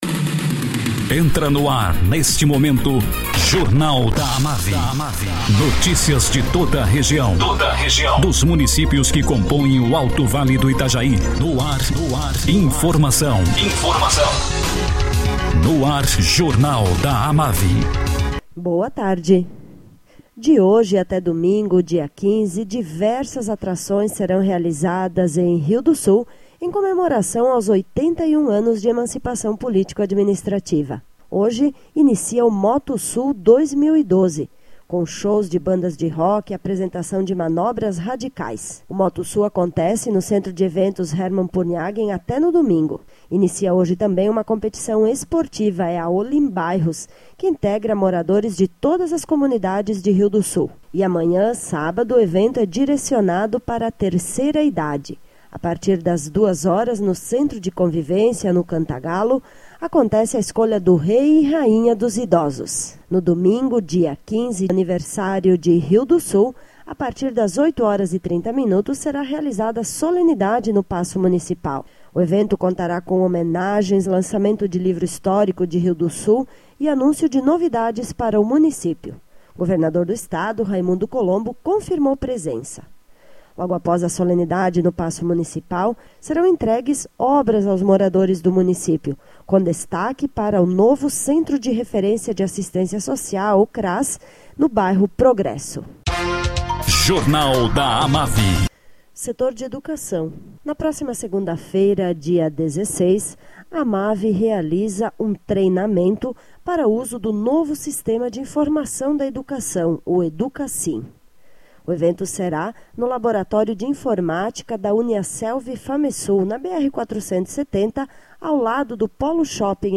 Acervo de boletins